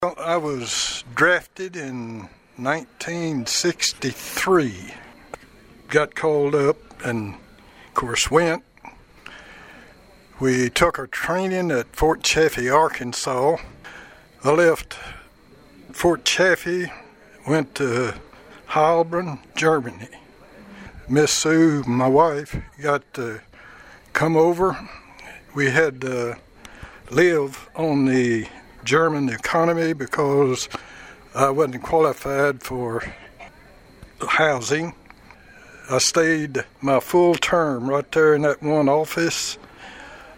The Pleasant Grove Baptist Church located on Hopkinsville Road in Princeton recognized Veterans on Saturday night with a fish meal prepared by the church members.